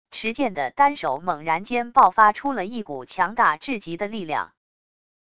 持剑的单手猛然间爆发出了一股强大至极的力量wav音频生成系统WAV Audio Player